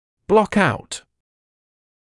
[blɔk aut][блок аут]загораживать; закрывать; скрывать из виду; блокировать